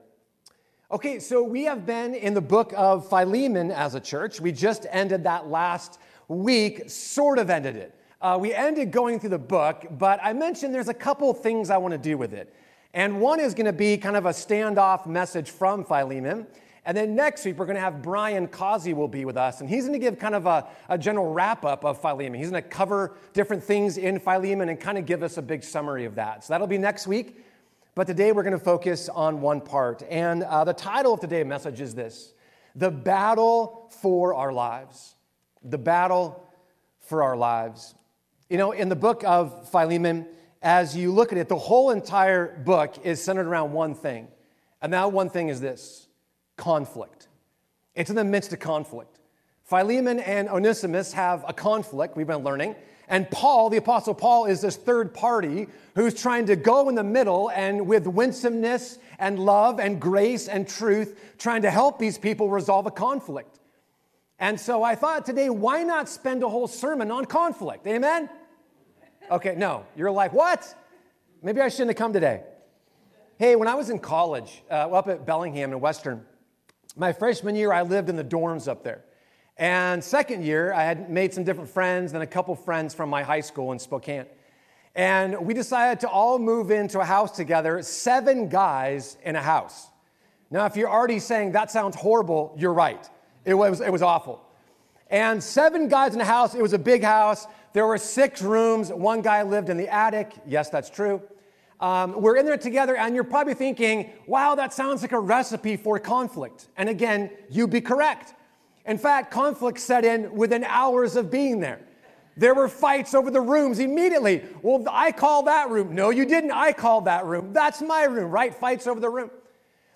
Sermons | Arbor Heights Community Church